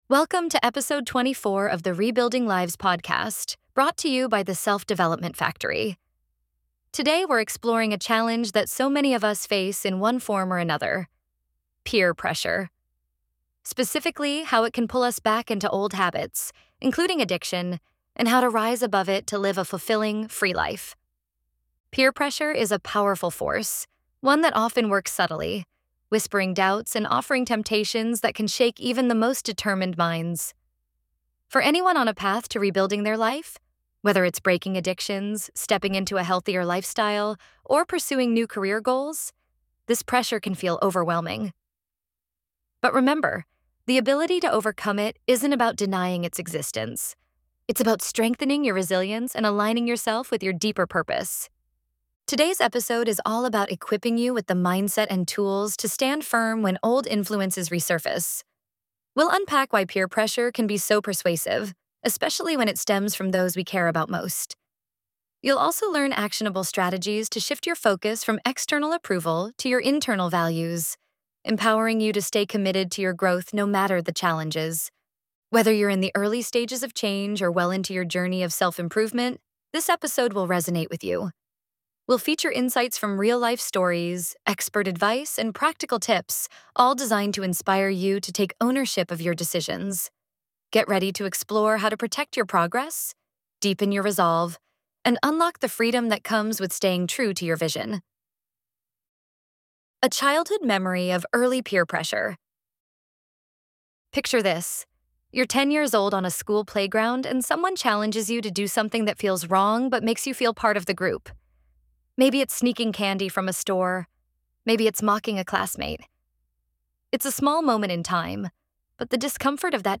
In this episode, we explore how peer influence shows up in social settings, family dynamics, and even within recovery communities themselves. Through candid conversations with individuals in long-term recovery and expert advice from addiction counselors, we unpack strategies for maintaining boundaries, building confidence, and choosing support systems that lift you up.